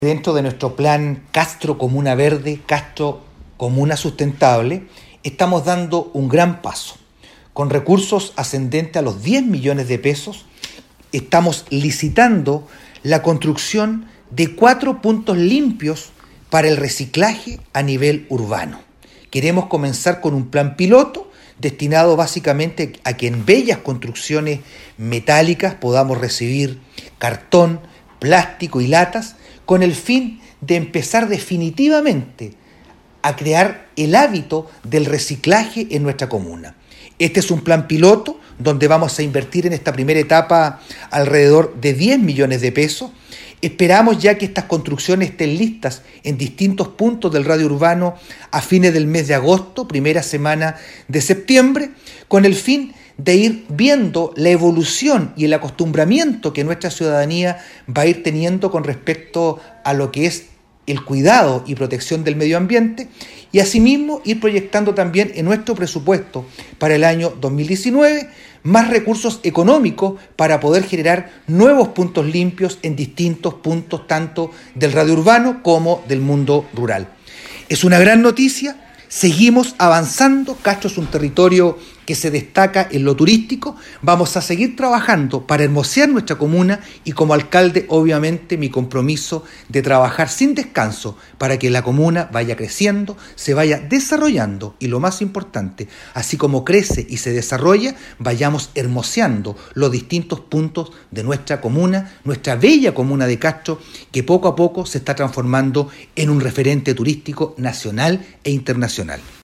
cuña-alcalde-vera-tema-castro-comuna-verde.mp3